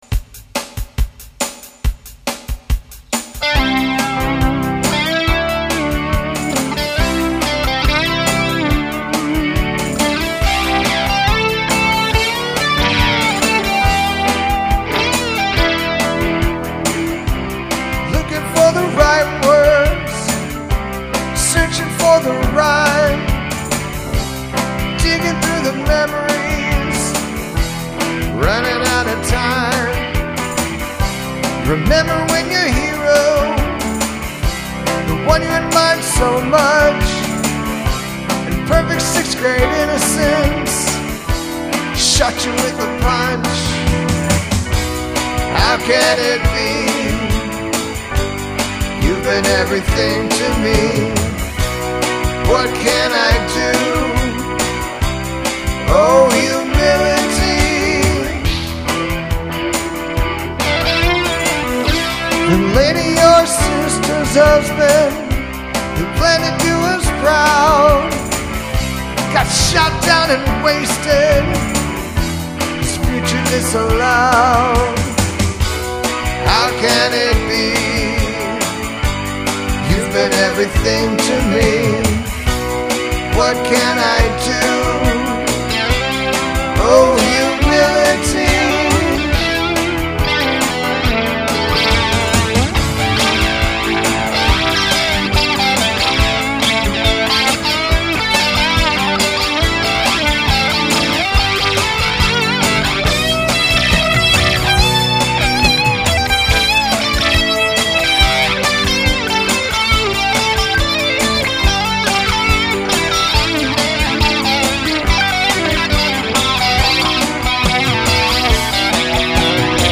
149 b/m 6/23/20